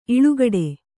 ♪ iḷugaḍe